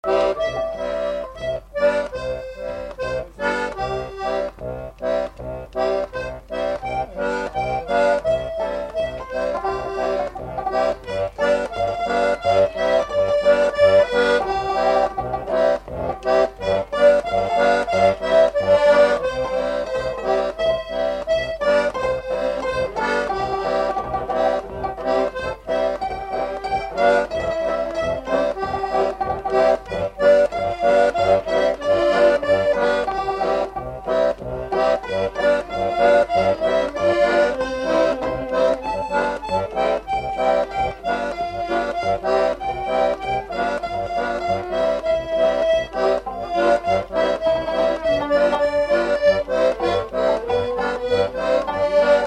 Mémoires et Patrimoines vivants - RaddO est une base de données d'archives iconographiques et sonores.
Instrumental
Pièce musicale inédite